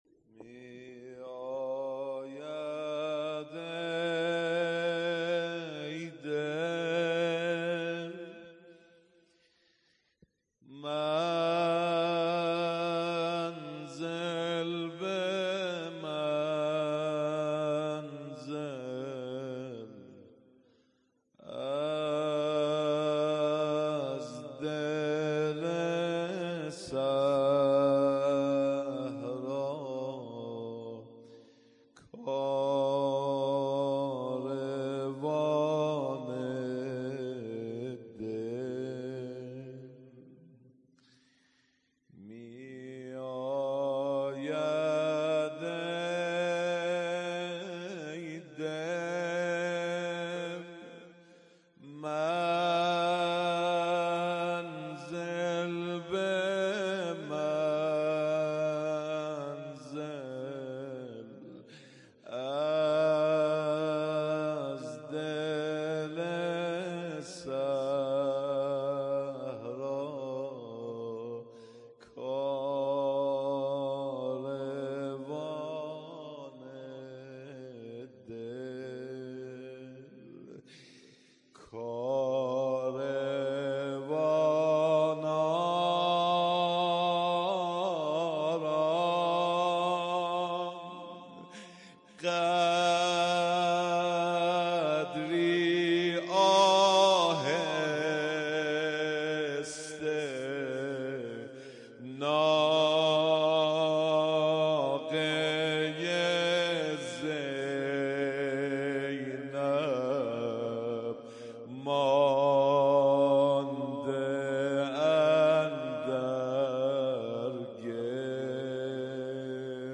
روضه خوانی
شب دوم محرم ۱۴۰۱
هیئت عقیله بنی هاشم سبزوار